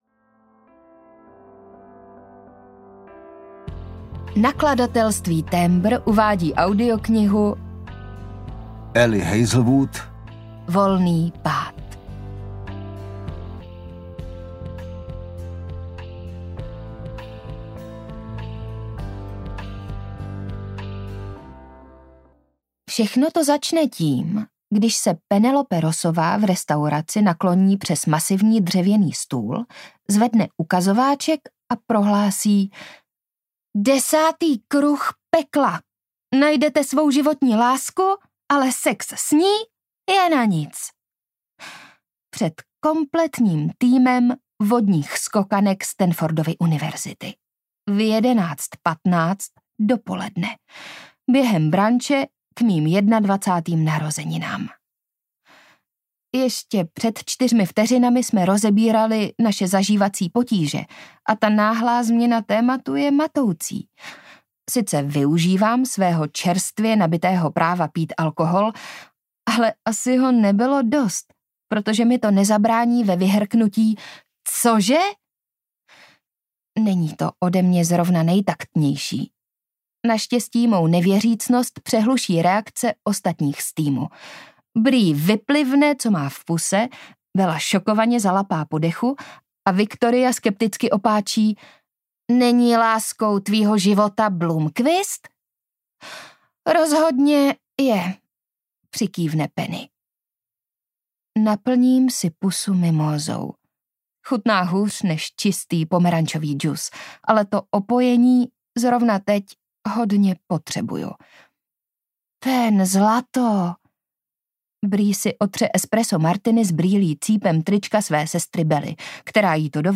Audiokniha Volný pád, kterou napsala Ali Hazelwood.
Ukázka z knihy
volny-pad-audiokniha